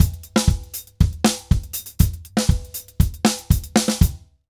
Drums_Merengue 120_1.wav